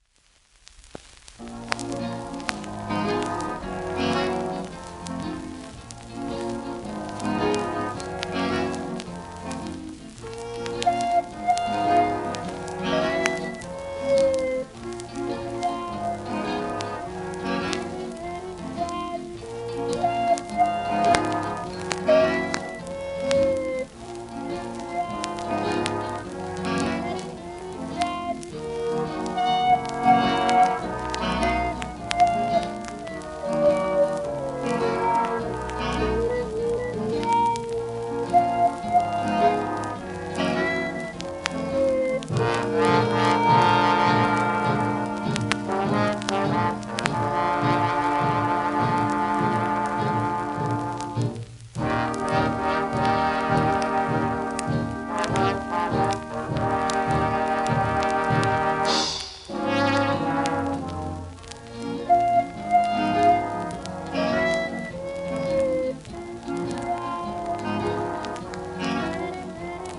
盤質B+ *小キズ,面スレ
1939年録音